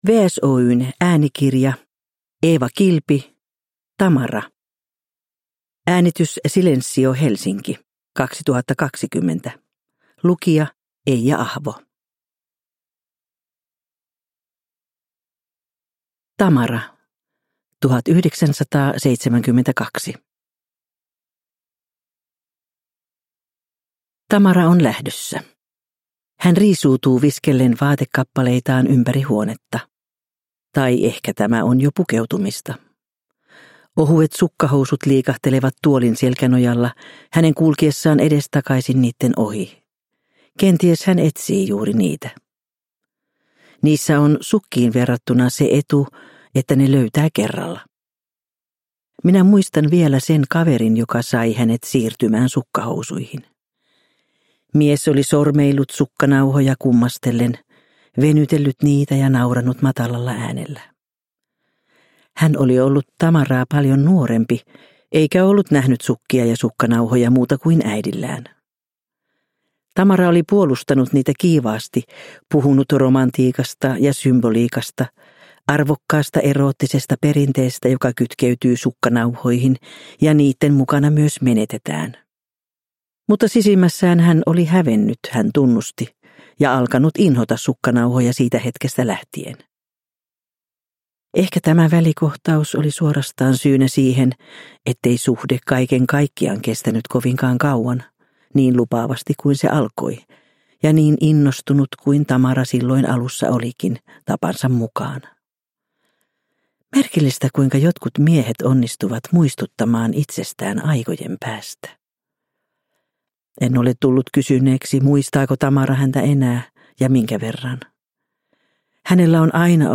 Tamara (ljudbok) av Eeva Kilpi